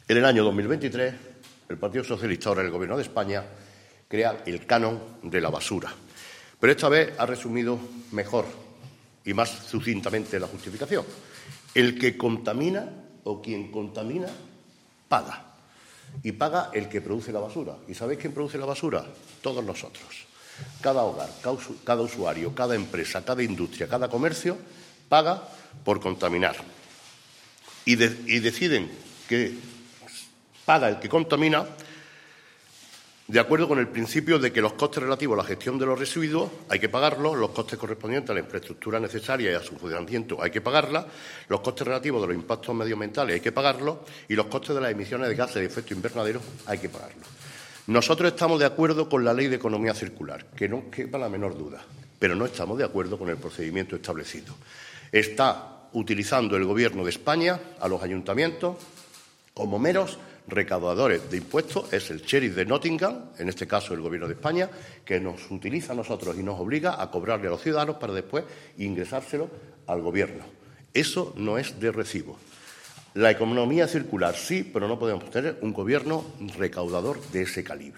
El alcalde de Antequera, Manolo Barón, el teniente de alcalde delegado de Hacienda y Aguas del Torcal, Antonio García Acedo, y el concejal de Medio Ambiente, José Manuel Fernández, han comparecido en rueda de prensa para denunciar públicamente la utilización que esta llevando a cabo el Gobierno de España sobre los ayuntamientos a la hora de obligarlos a actuar como “meros recaudadores de impuestos” en relación a la creación del nuevo Impuesto sobre el Depósito de Residuos en Vertederos, la Incineración y la Coincineración (IDRV).
Cortes de voz